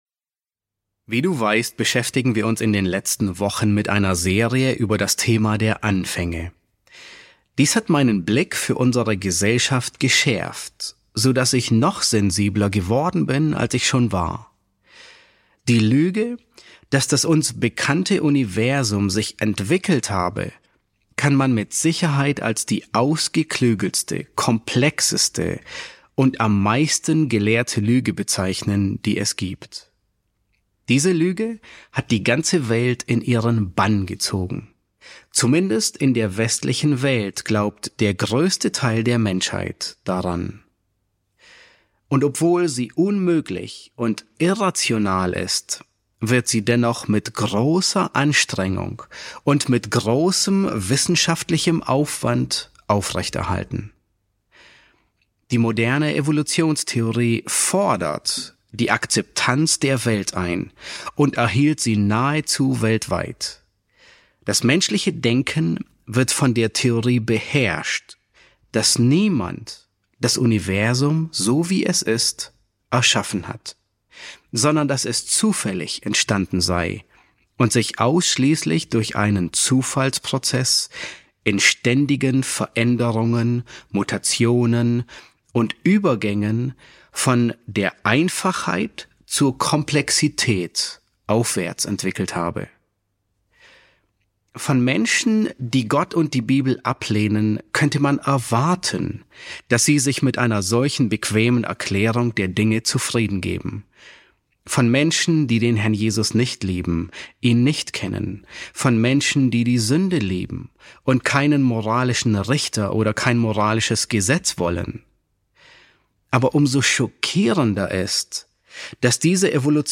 E5 S6 | Das Wie, Warum und Wann der Schöpfung, Teil 2 ~ John MacArthur Predigten auf Deutsch Podcast